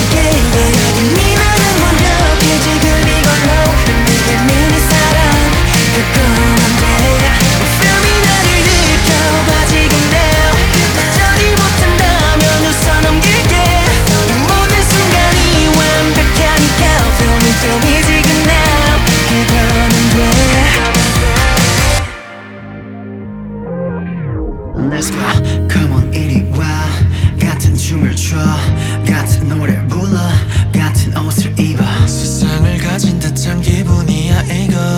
Жанр: K-pop / Поп